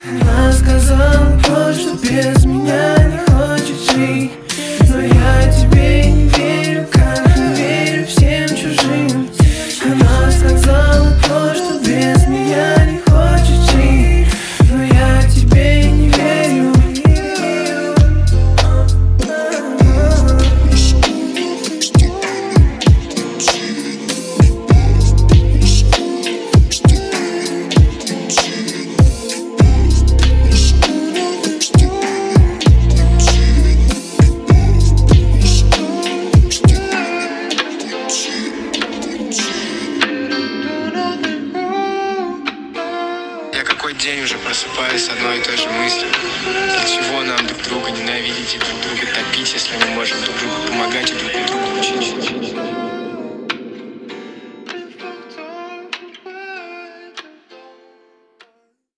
• Качество: 128, Stereo
грустные
русский рэп
спокойные
романтичные